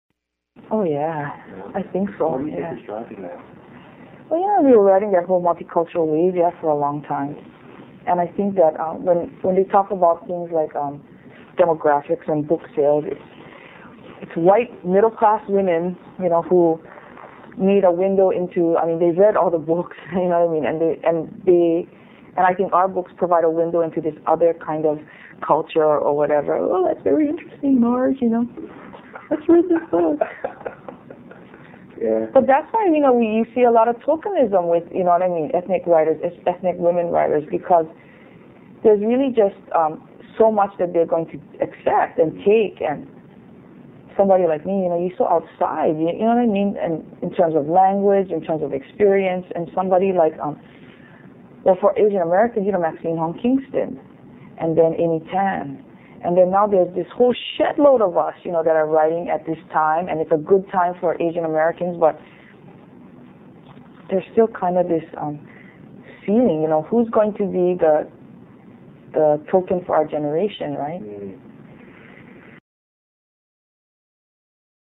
Q&A with Lois-Ann Yamanaka